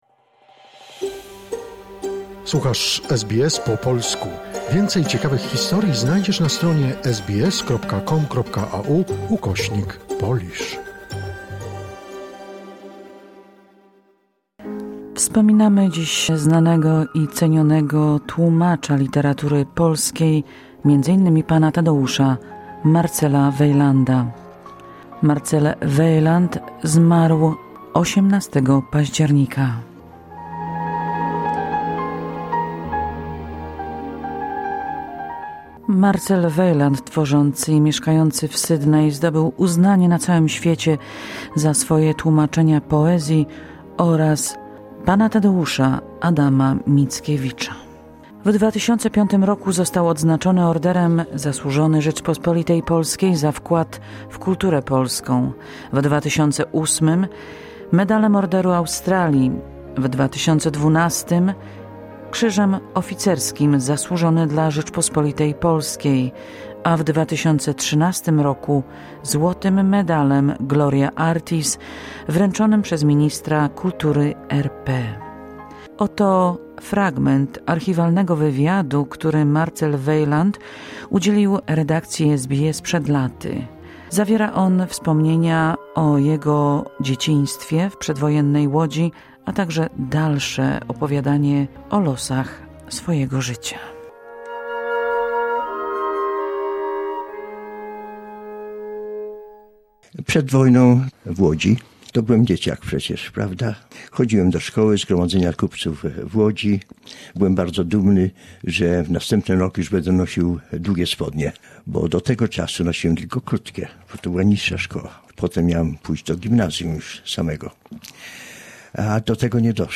Lata temu w naszym studio opowiadał o swoim życiu i miłości do polskiej poezji i literatury.